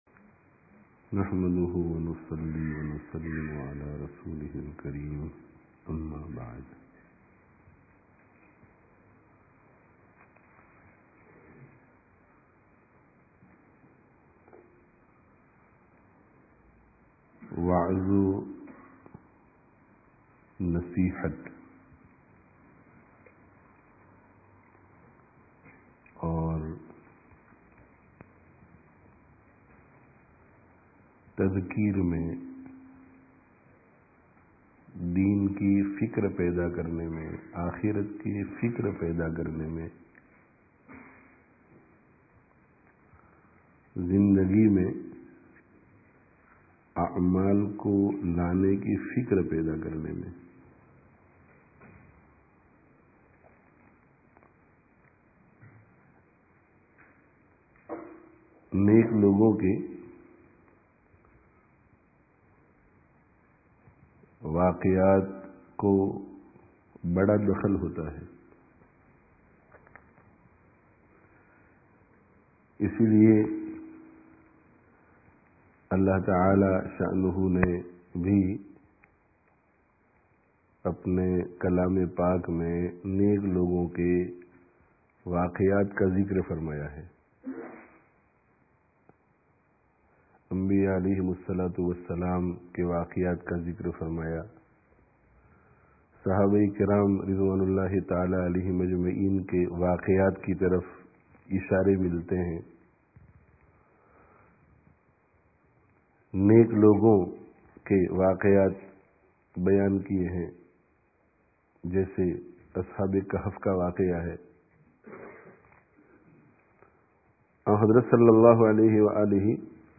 friday tazkiyah gathering